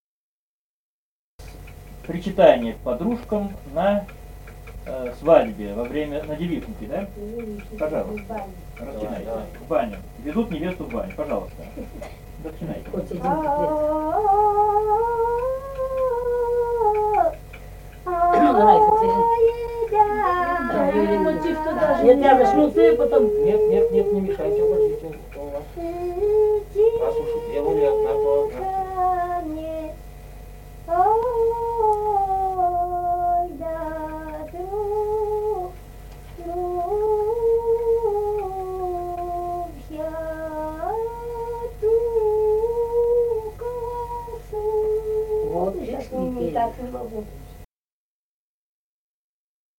Республика Казахстан, Восточно-Казахстанская обл., Катон-Карагайский р-н, с. Фыкалка, июль 1978.